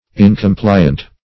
Search Result for " incompliant" : The Collaborative International Dictionary of English v.0.48: Incompliant \In`com*pli"ant\, a. Not compliant; unyielding to request, solicitation, or command; stubborn.